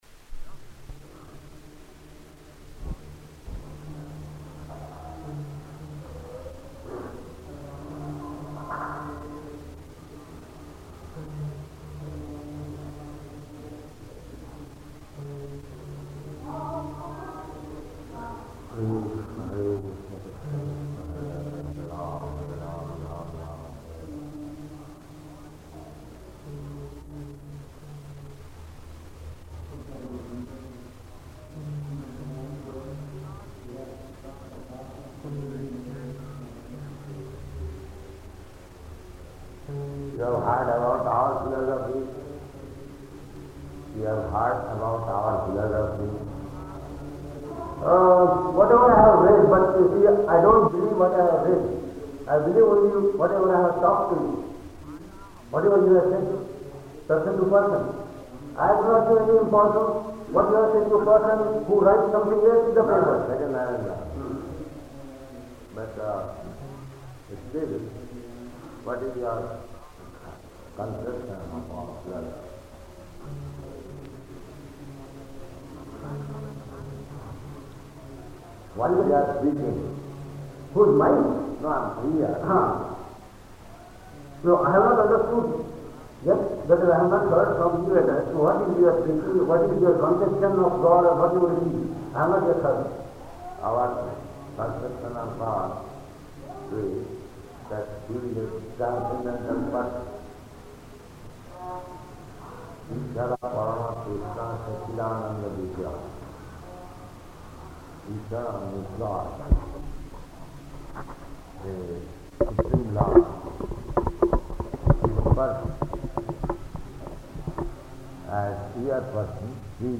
Room Conversation
Room Conversation --:-- --:-- Type: Conversation Dated: December 13th 1970 Location: Indore Audio file: 701213R2-INDORE.mp3 Prabhupāda: [ japa ] You have heard about our philosophy?
Guest (1) (Indian man): Whatever I have read.